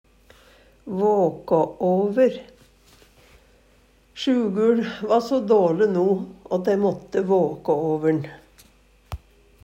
DIALEKTORD PÅ NORMERT NORSK våkå over vake over, vera vaken om natta for å hjelpe/passe på ein sjuk/døyande.